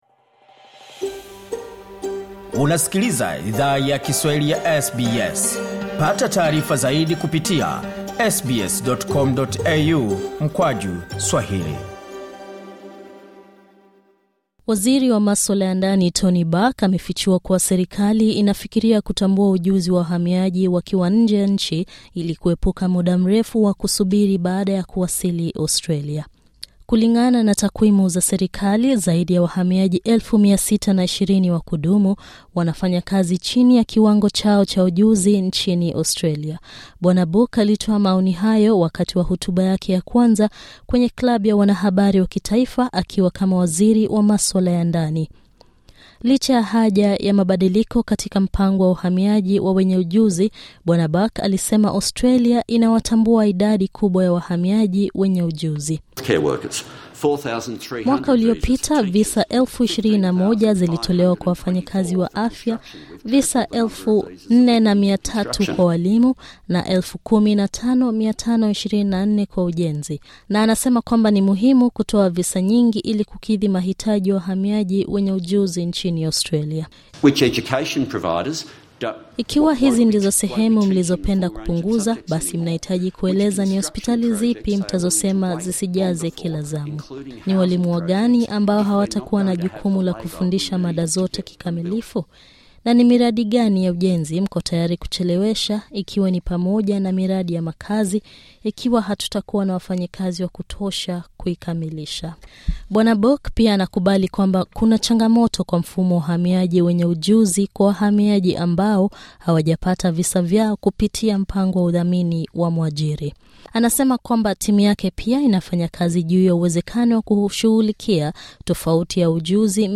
Waziri wa Mambo ya Ndani, Tony Burke, anasema kwamba serikali inafanya kazi juu ya uwezekano wa kutambua ujuzi wa nje ya nchi. Anasema hii itasaidia kuokoa muda na pesa kwa wahamiaji wenye ujuzi wanaotafuta kuhamia Australia. Katika hotuba yake kwa Klabu ya Waandishi wa Habari huko Canberra, Bw. Burke pia ametangaza mabadiliko kwenye akaunti za benki zisizotumika ili kukabiliana na utakatishaji wa fedha.